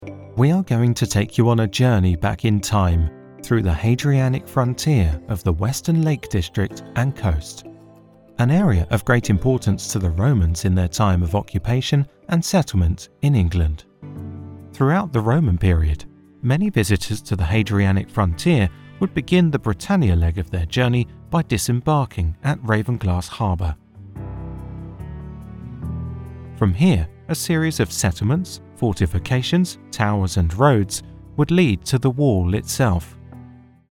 English (British)
Natural, Friendly, Corporate, Commercial, Warm
Audio guide